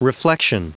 Prononciation du mot reflection en anglais (fichier audio)